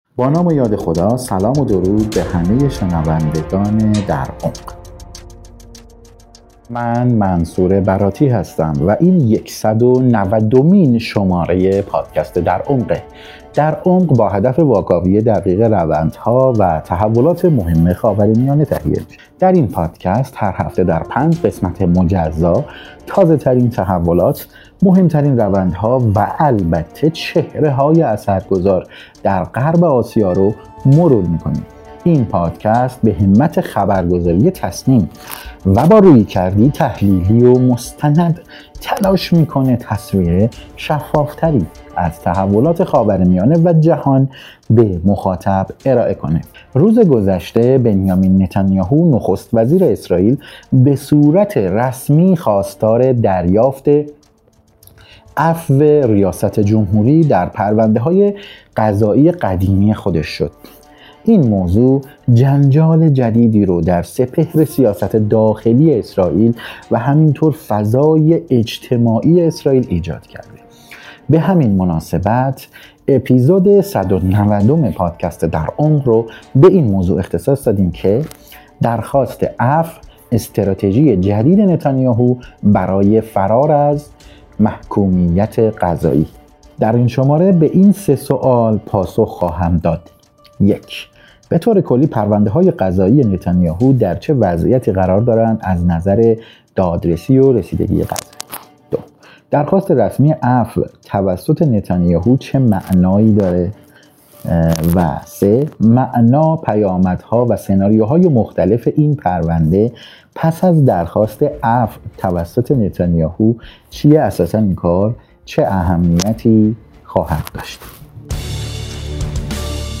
کارشناس مسائل اسرائیل